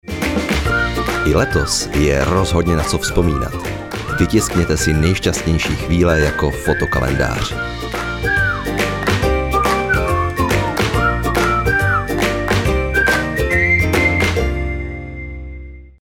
Umím: Voiceover
Mužský voiceover do Vašich videí